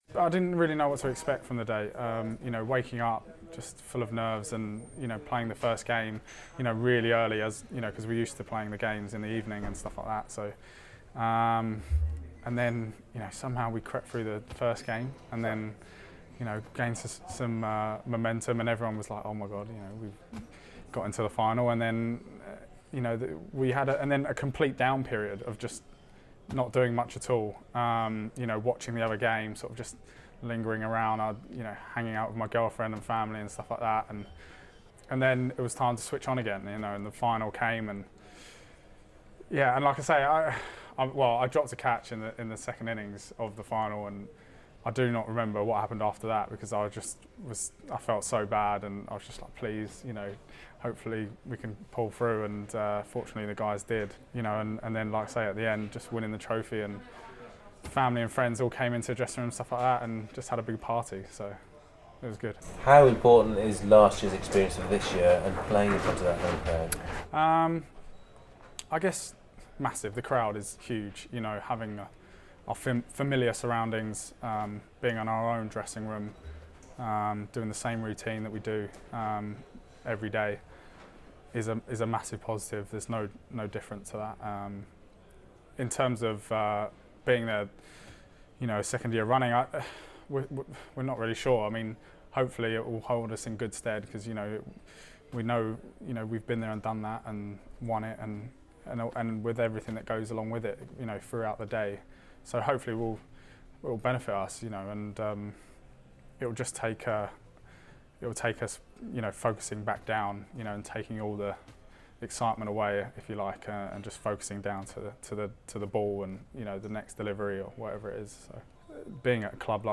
Birmingham Bears batsman Laurie Evans reflects on last year's final and speaks to BBC WM ahead of T20 Finals Day at Edgbaston on Saturday.